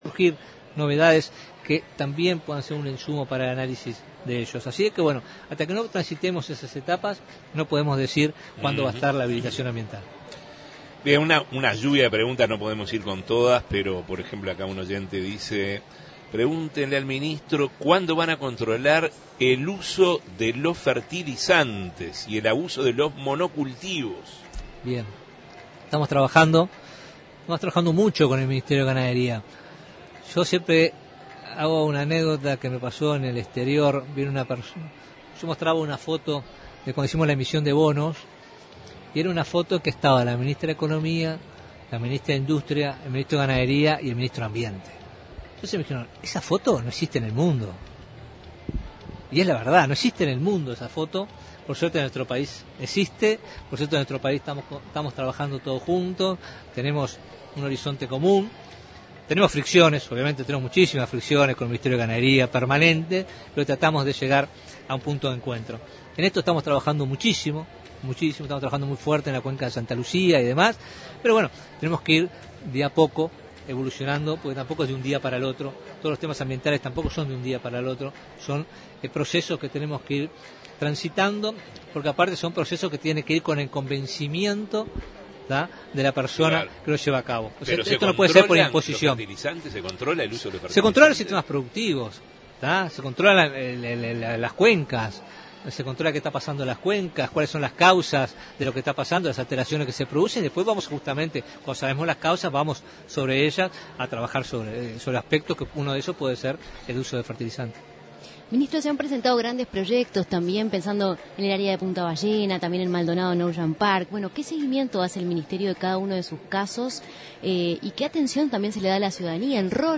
El ministro de Ambiente, Robert Bouvier, se refirió a distintos temas que atañen a su cartera, al tiempo que destacó eventos como la Expo Uruguay Sostenible 2024 de manera de crear conciencia social sobre el cuidado ambiental.